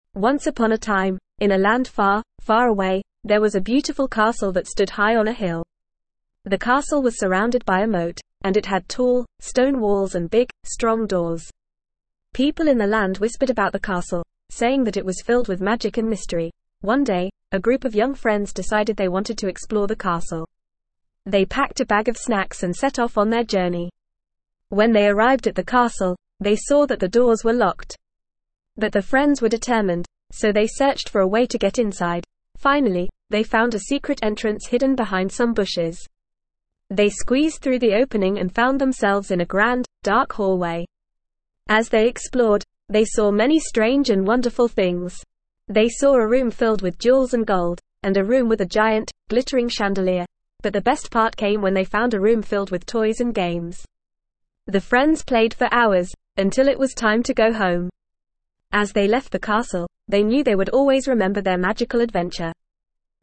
Normal
ESL-Short-Stories-for-Kids-NORMAL-reading-The-Mysterious-Castle.mp3